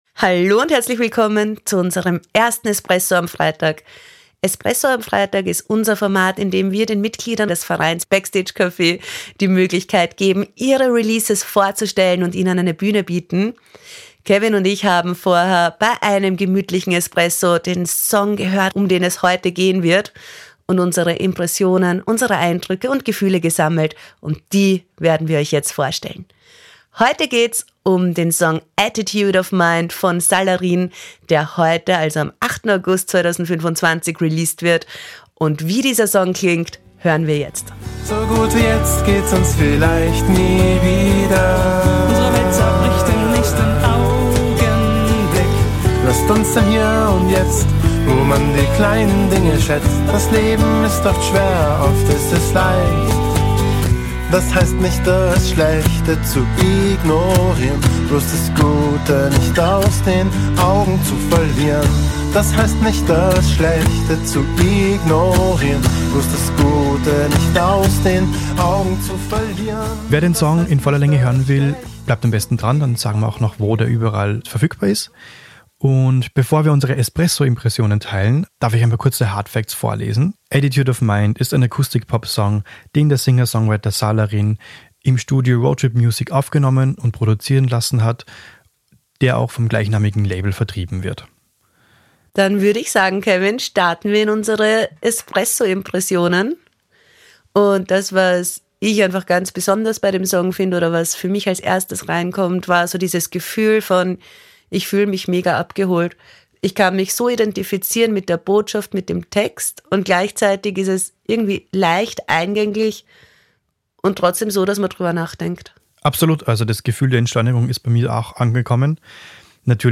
der Podcast von und für Musiker:innen